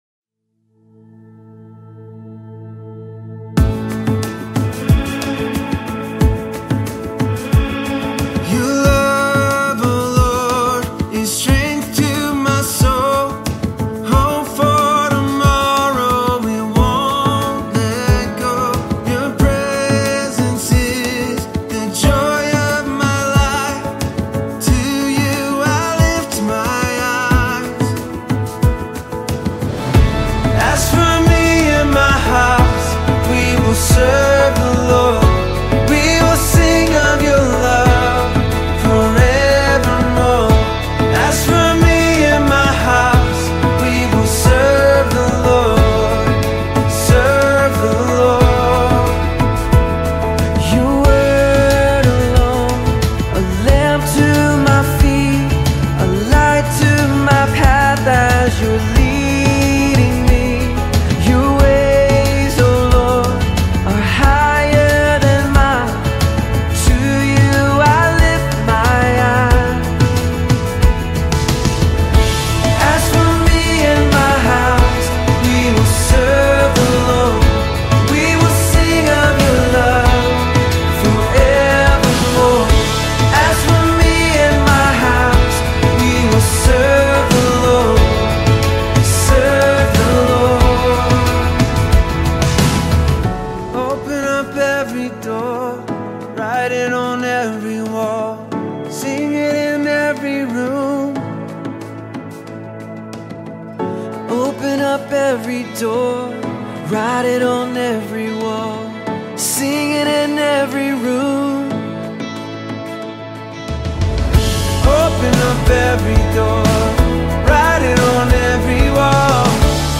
latest studio project